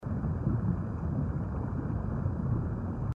ambientwater.mp3